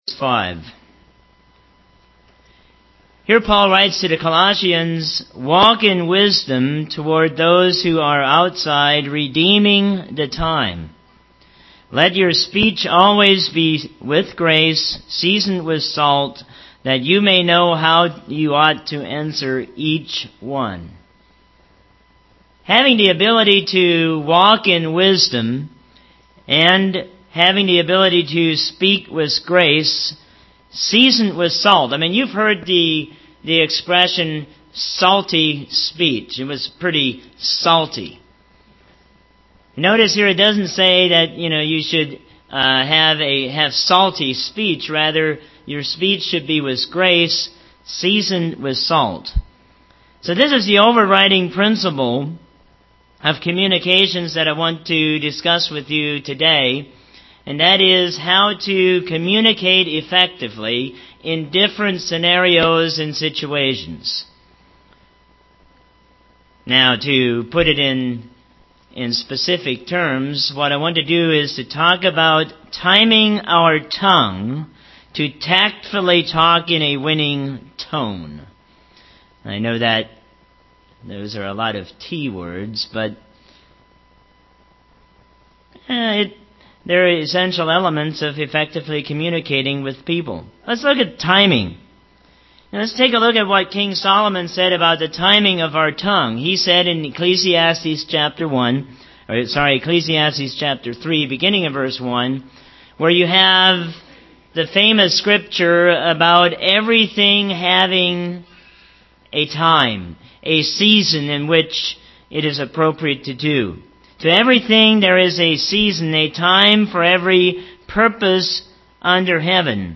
Think, Timing, Tact and Tone UCG Sermon Studying the bible?